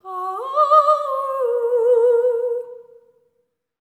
LEGATO 04 -R.wav